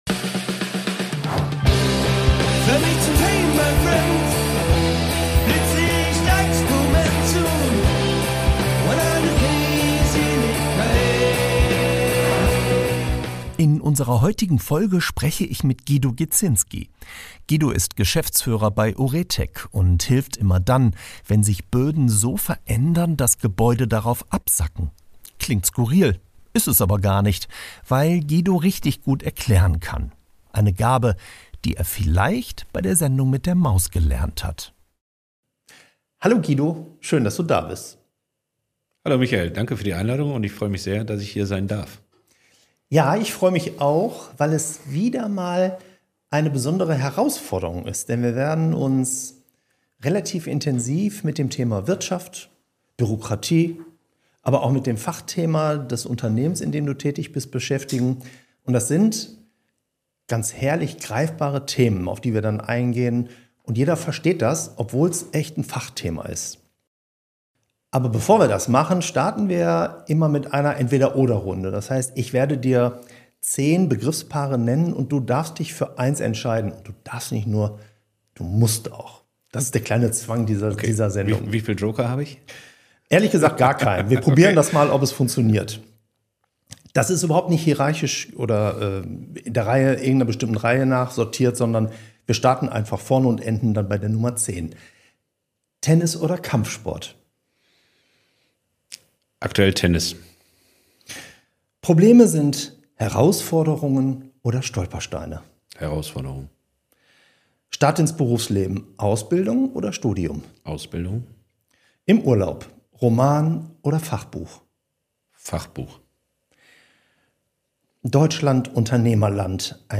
Und trotzdem klingt er im Gespräch überraschend bodenständig.